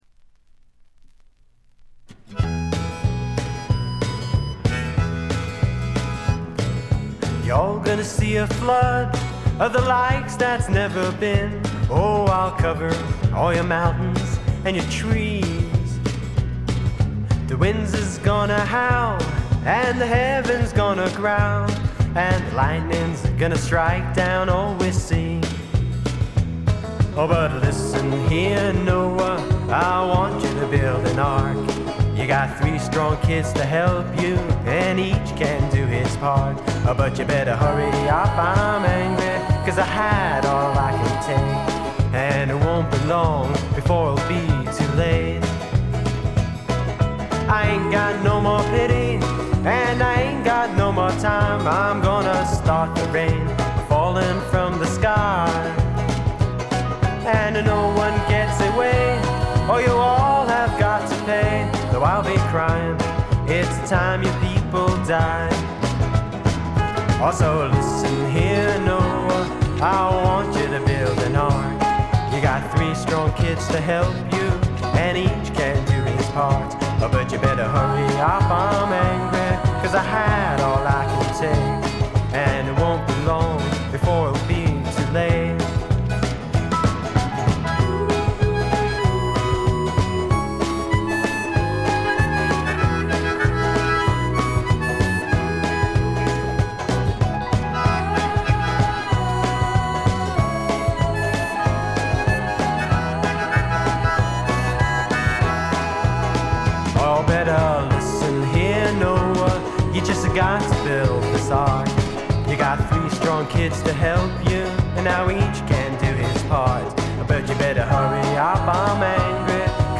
ほとんどノイズ感無し。
演奏、歌ともに実にしっかりとしていてメジャー級の85点作品。
試聴曲は現品からの取り込み音源です。